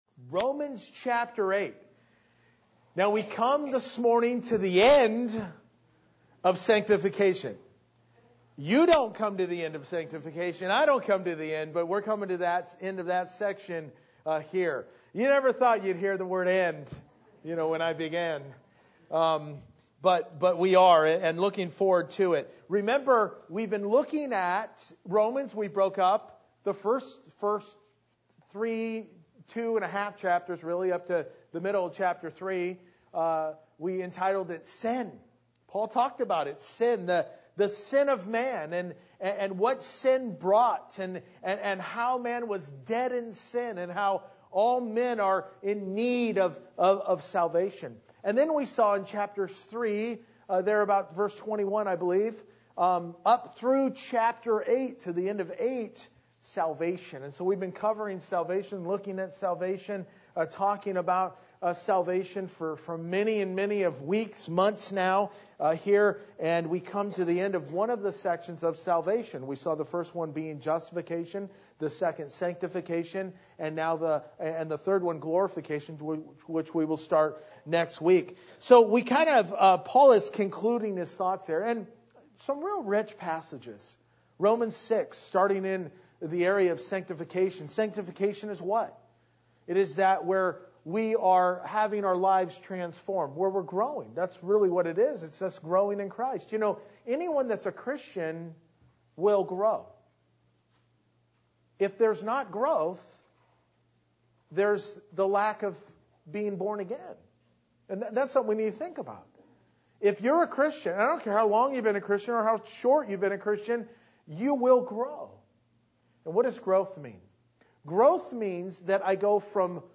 Verse by Verse-In Depth